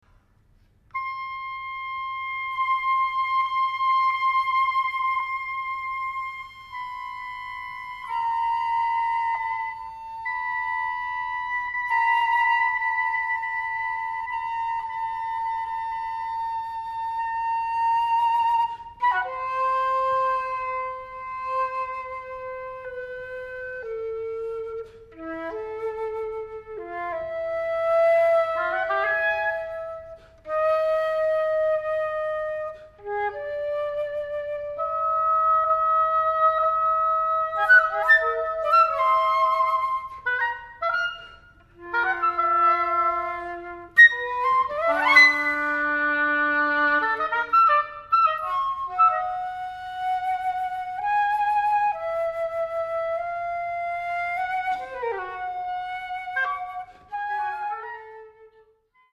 Five Epigrams for Flute and Oboe